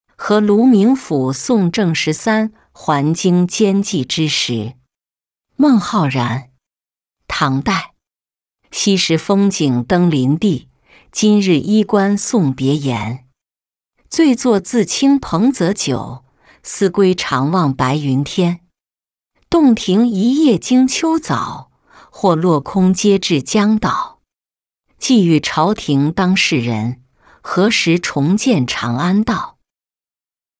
和卢明府送郑十三还京兼寄之什-音频朗读